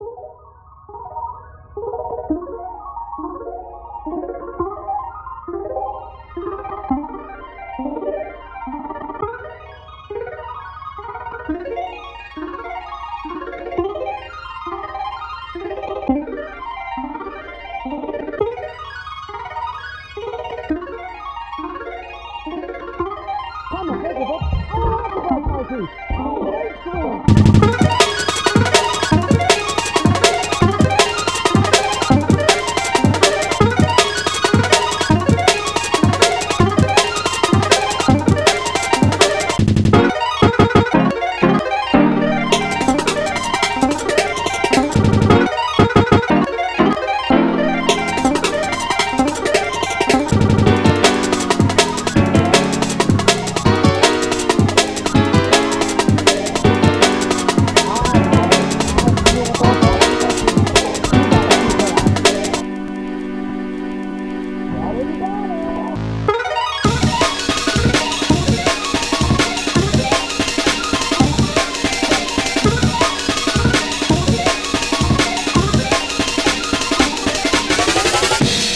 be warned like most ofthis shit is just dnb/jungle